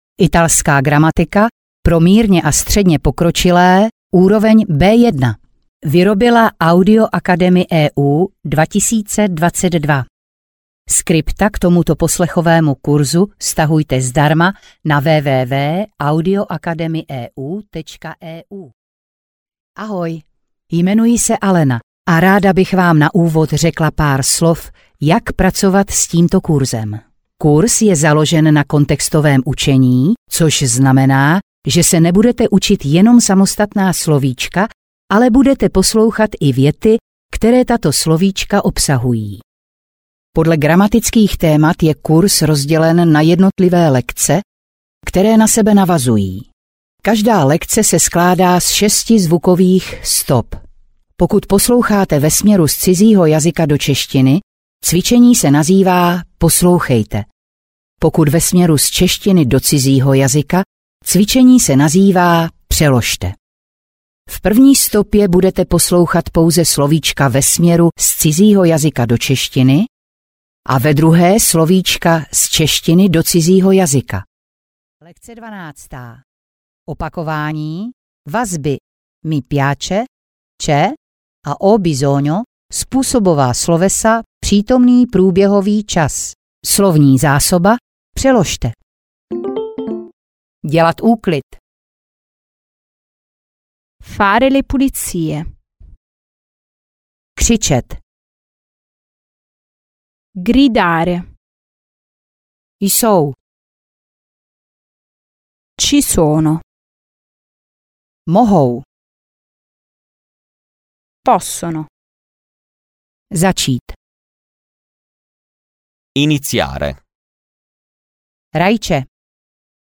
Italština - gramatika pro mírně a středně pokročilé B1 - audioacaemyeu - Audiokniha